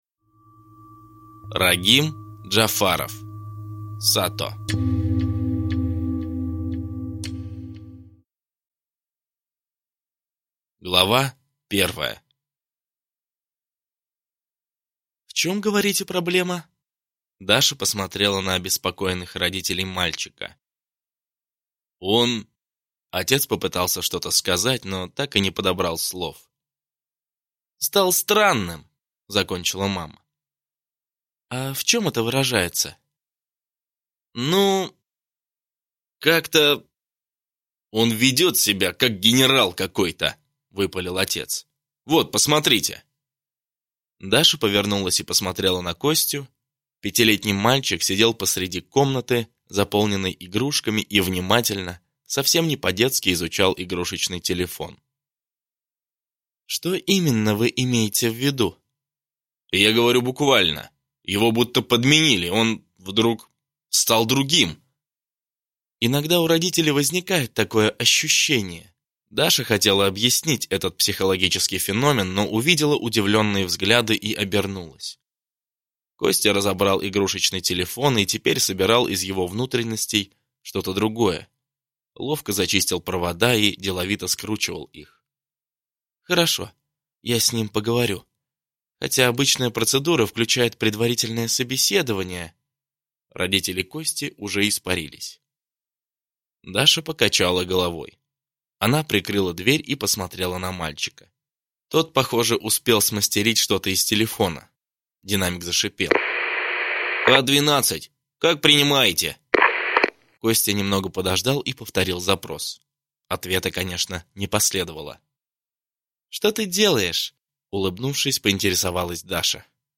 Аудиокнига Сато | Библиотека аудиокниг
Прослушать и бесплатно скачать фрагмент аудиокниги